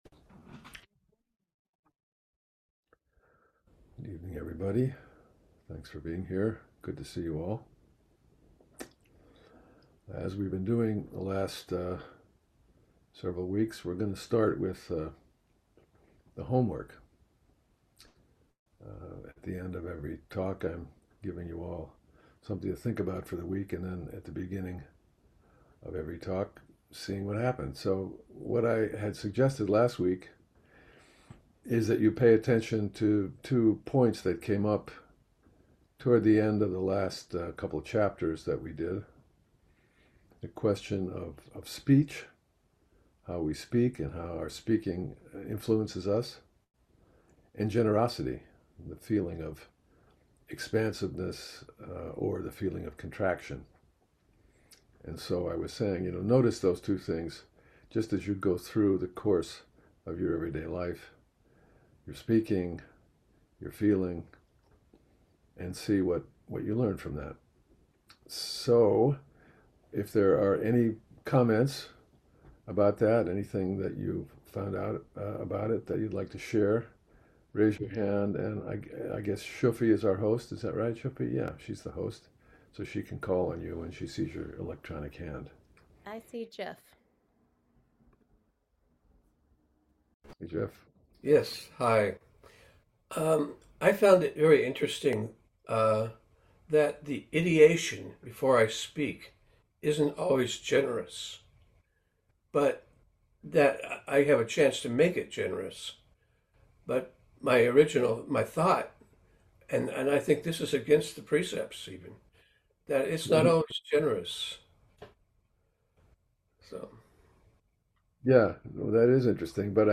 gives the tenth talk of the Dhammapada series to the Everyday Zen dharma seminar. The Dhammapada or “Path of Dharma” is a collection of verses in the Pali Canon that encapsulates the Buddha’s teachings on ethics, meditation and wisdom and emphasizes practical guidance for living a virtuous life.